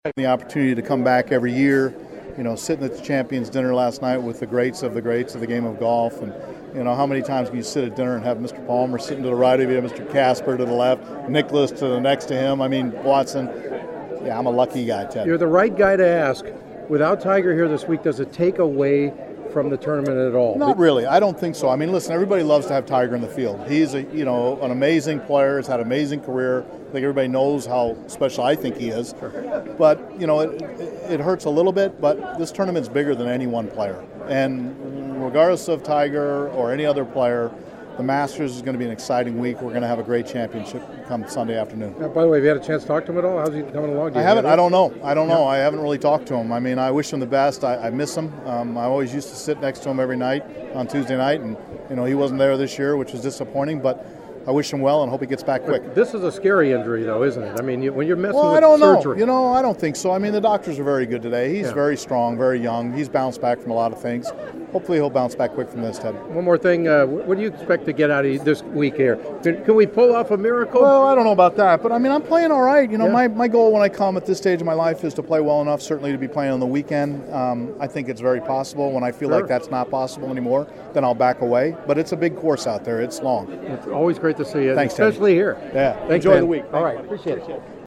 The following are some of my Masters preview interviews leading into Thursday’s first round.
on Wednesday at the Masters